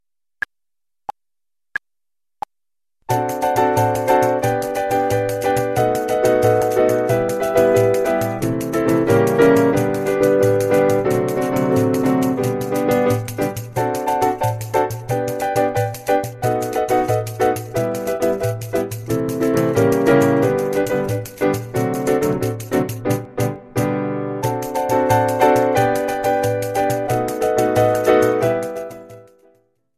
Version with the piano and pandeiro   US$ 2.50